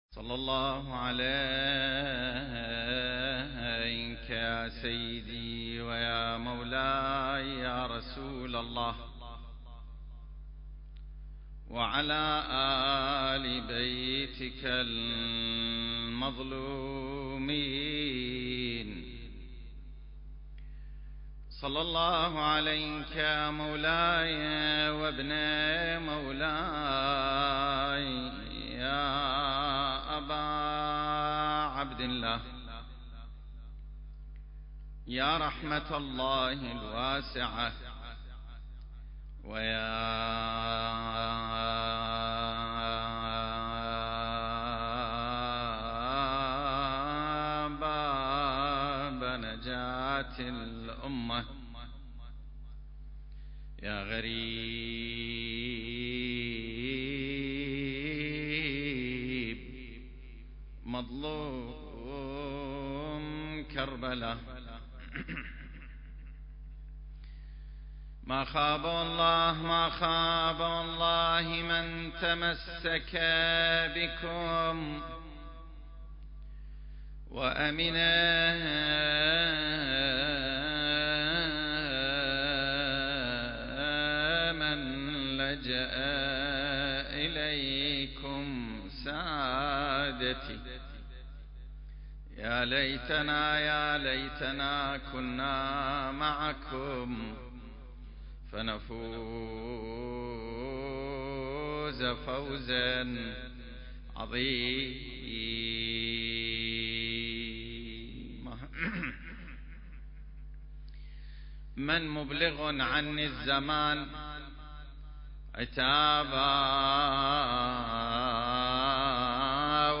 سلسلة محاضرات: نفحات منبرية في السيرة المهدوية المكان